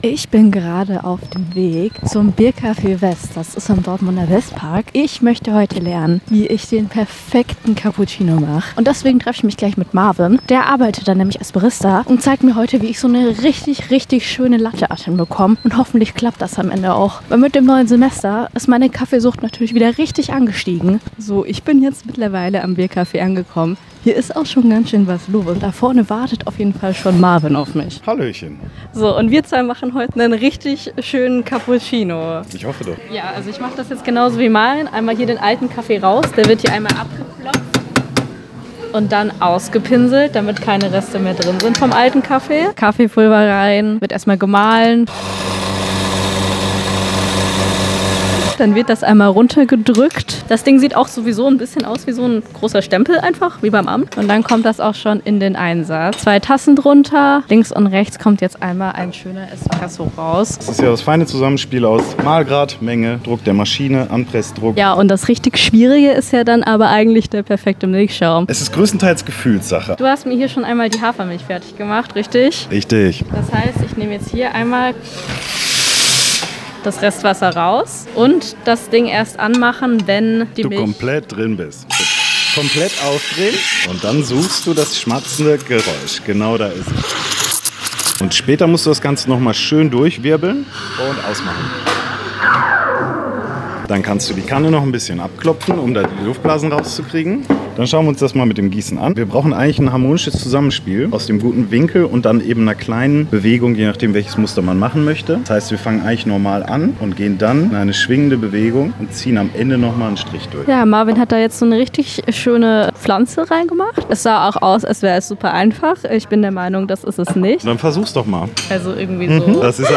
Serie: Reportage